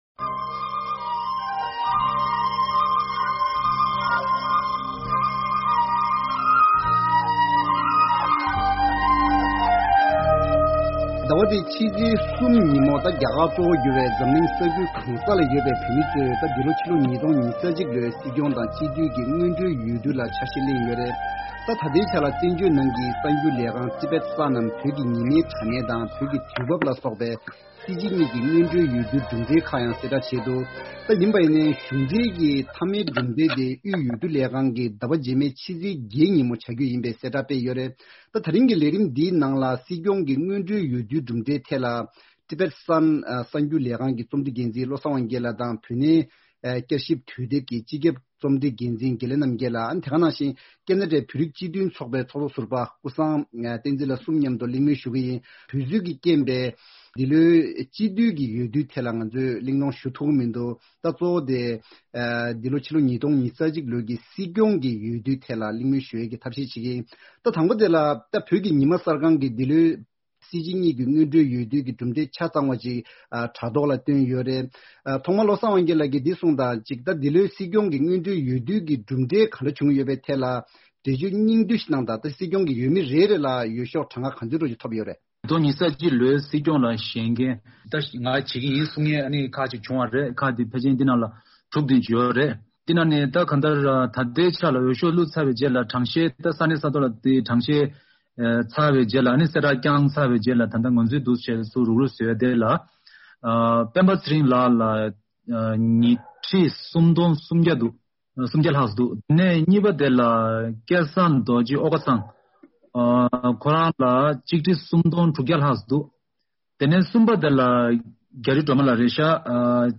ད་རིང་གི་བགྲོ་གླེང་མདུན་ཅོག་གི་ལས་རིམ་ནང་སྲིང་སྐྱོང་གྱི་སྔོན་འགྲོའི་འོས་བསྡུའི་གྲུབ་འབྲས་སྐོར་དཔྱད་ཞིབ་དང་འབྲེལ་ནས་གླེང་མོལ་ཞུ་གི་ཡིན།